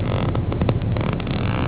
Index of /knowledgemedia/AU/EFFECTS/DOORS
CREAKY_3.AU